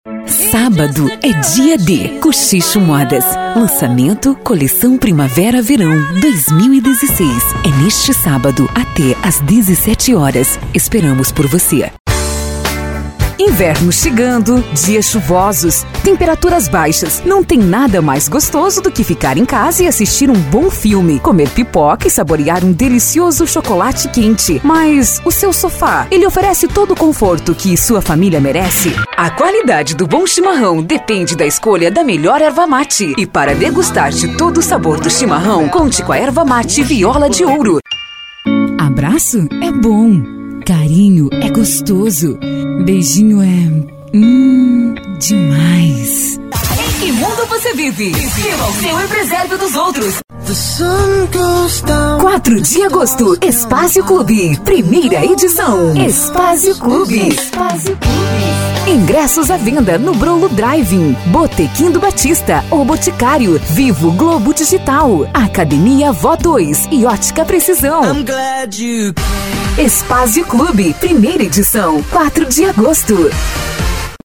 Voz demo.